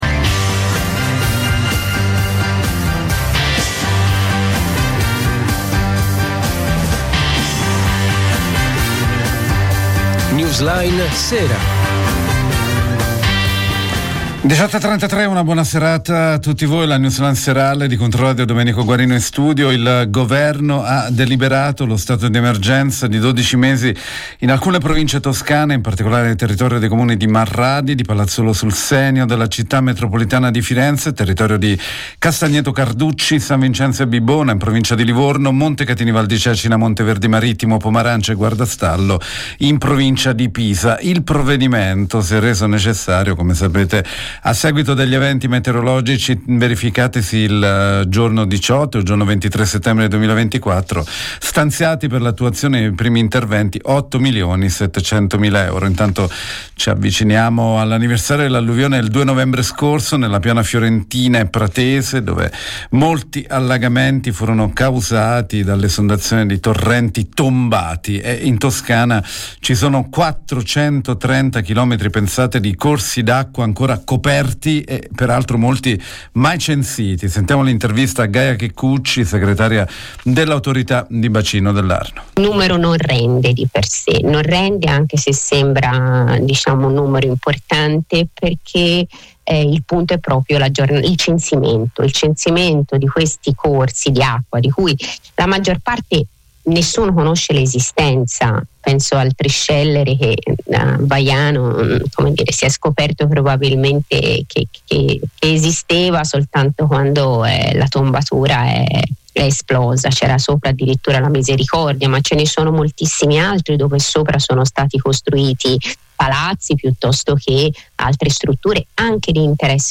L'edizione serale della newsline di Controradio